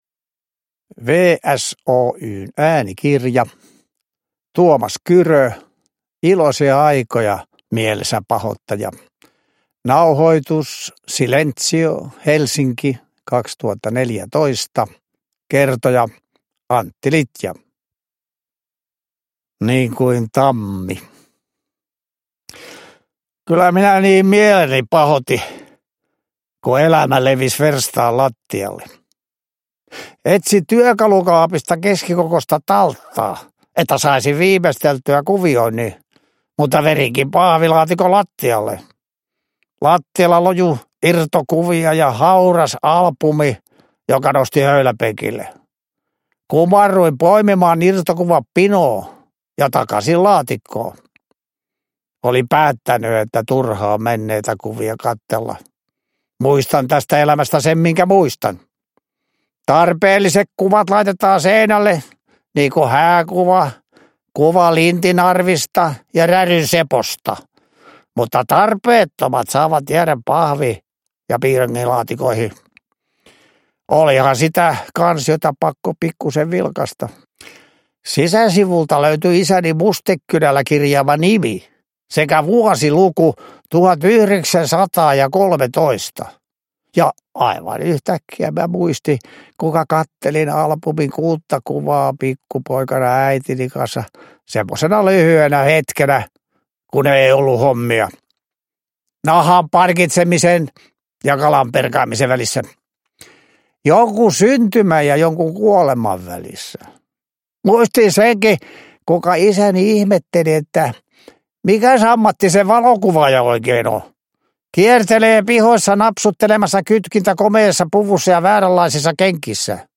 Ilosia aikoja, Mielensäpahoittaja – Ljudbok
Uppläsare: Antti Litja